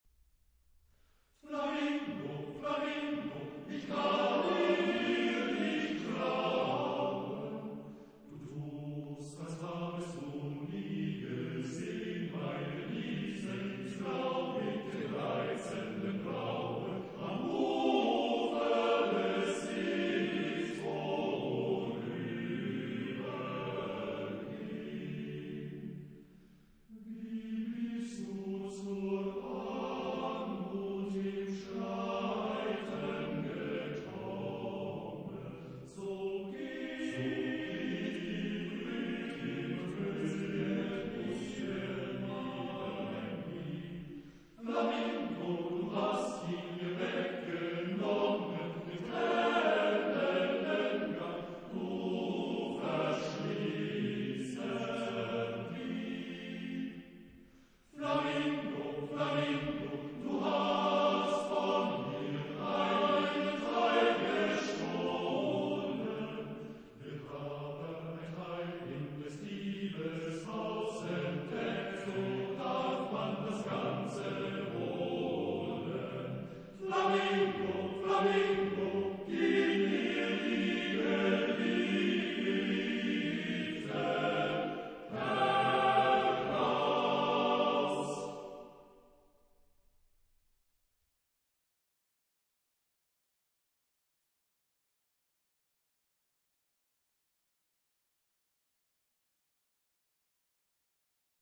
Epoque: 20th century
Genre-Style-Form: Secular ; Lied
Type of Choir: TTBB  (4 men voices )